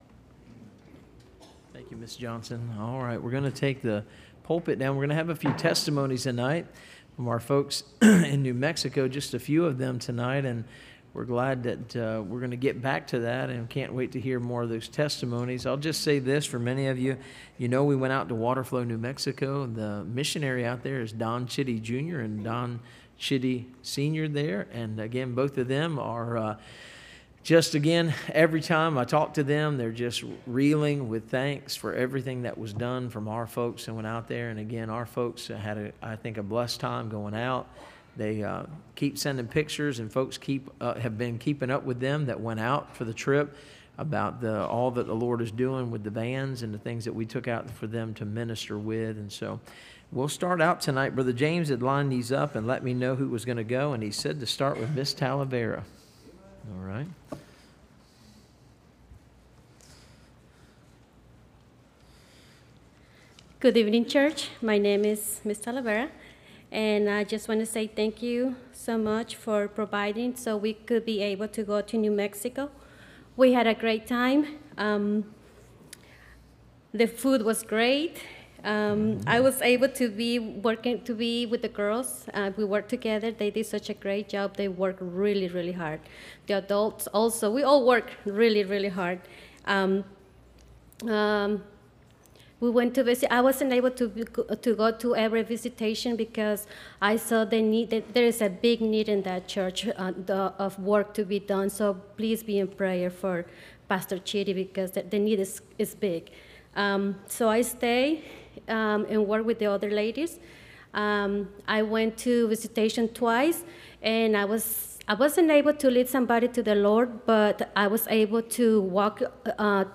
Series: Navajo Indians Trip Testimonies